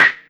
04PERC01  -L.wav